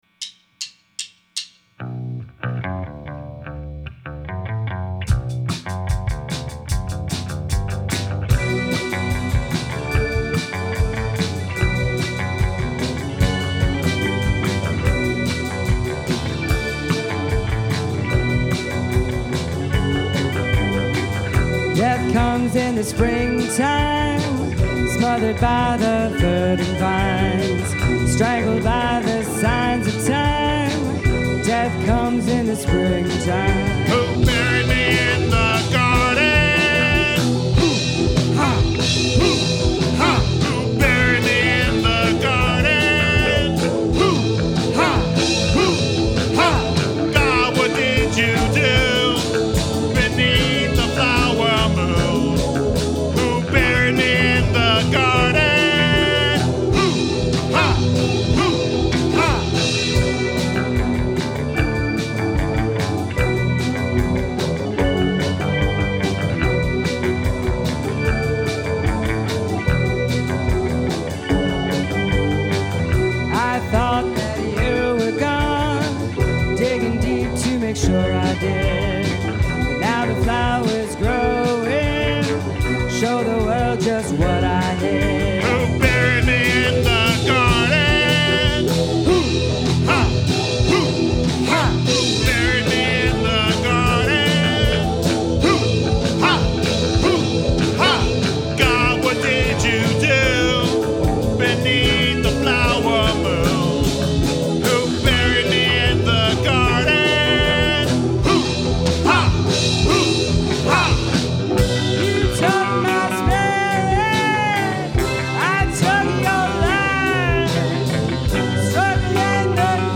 Narrative song about a murder
Strong delivery, love the who ha part.